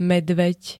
Zvukové nahrávky niektorých slov
hwk4-medved.ogg